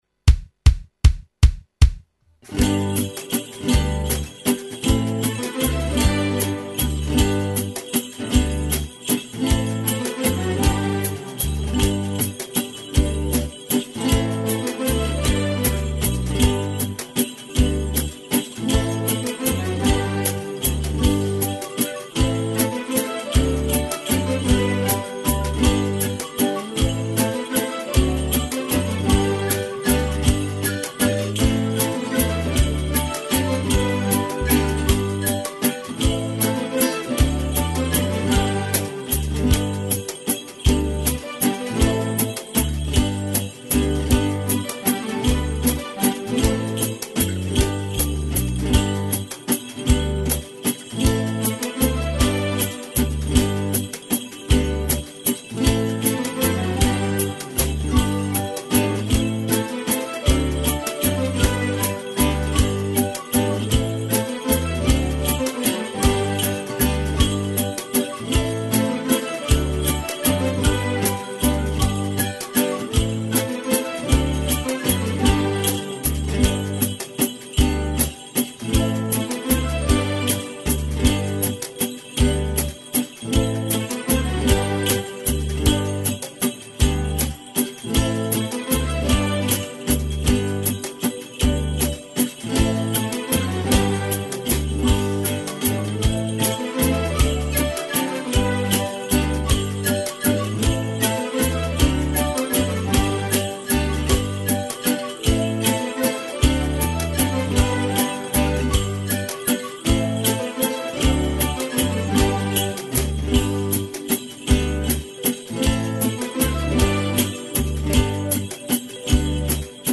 Télécharger le Playback